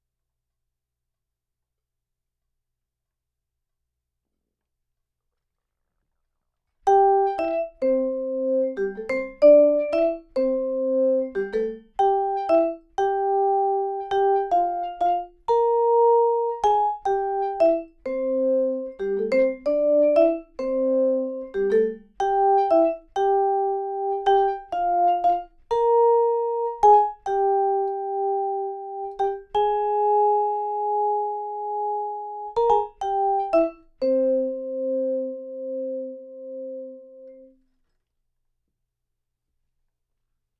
Vibes RM.01_04.R.wav